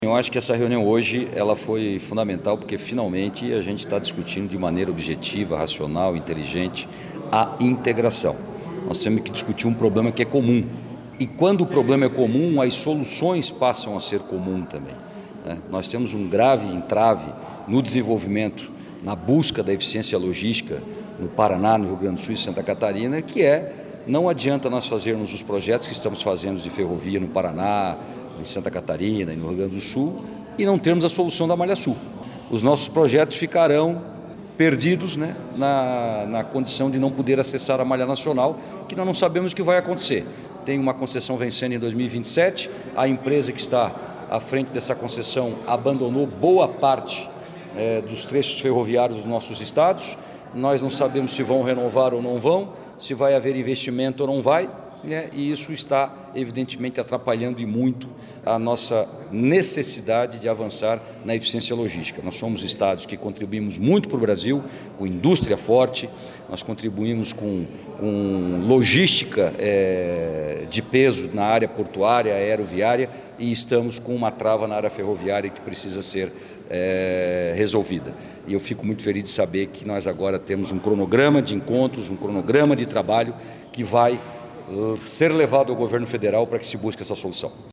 SECOM-Sonora-secretario-Beto-Martins-Cosud-Parana.mp3